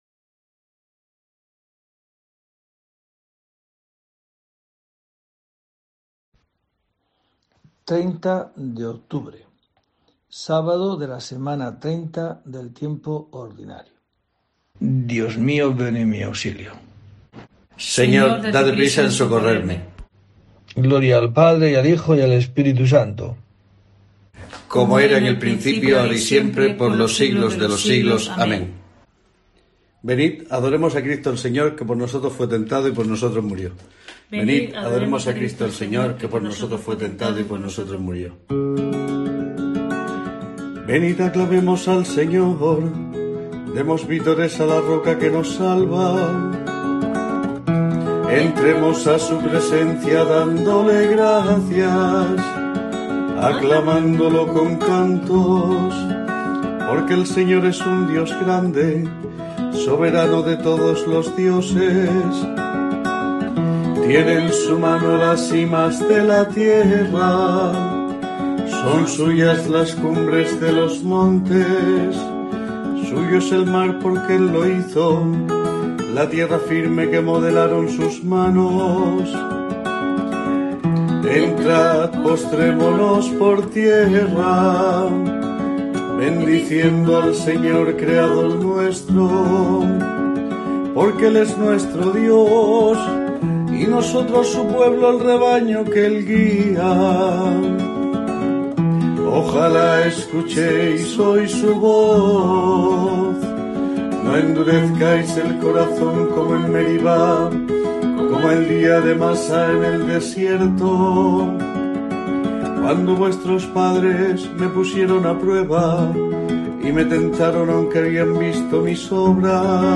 30 de octubre: COPE te trae el rezo diario de los Laudes para acompañarte